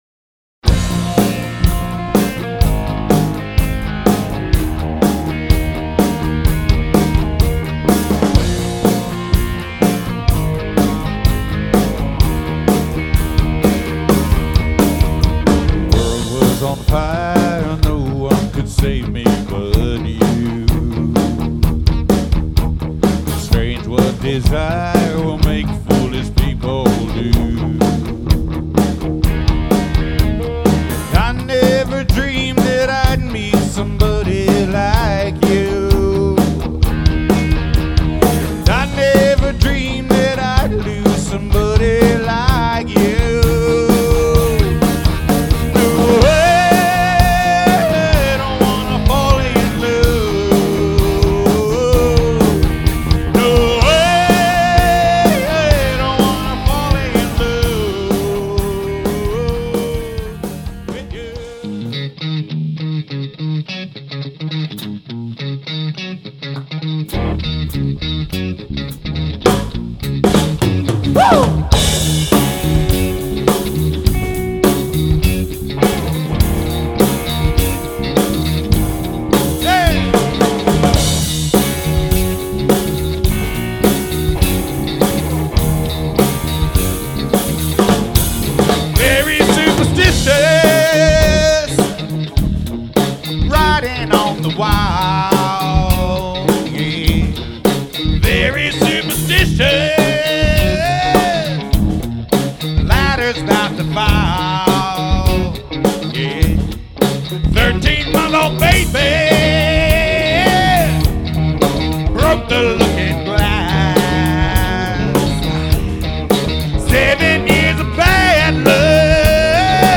Demotrack
Lead Vocals.
Electric Guitar.
Bass Guitar. Fundamental, bodenständig, verlässlich.